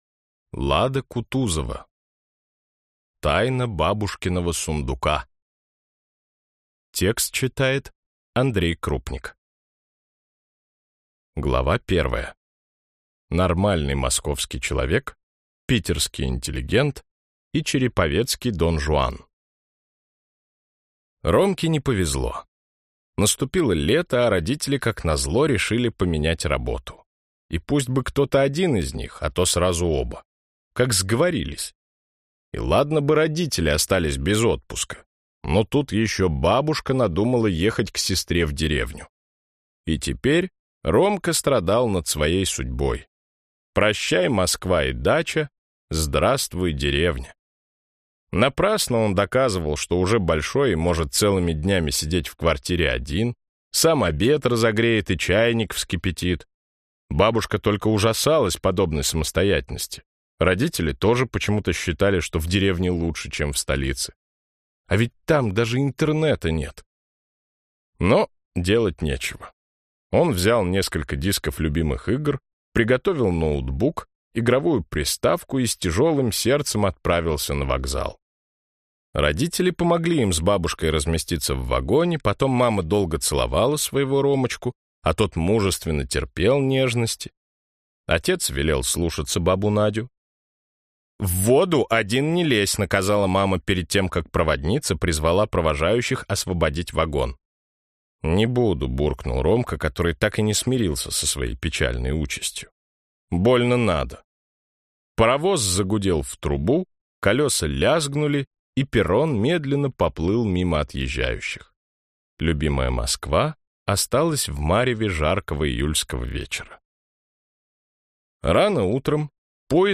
Аудиокнига Тайна бабушкиного сундука | Библиотека аудиокниг
Прослушать и бесплатно скачать фрагмент аудиокниги